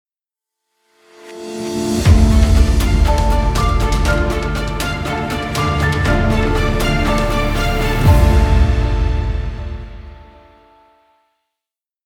News Report
Television News Report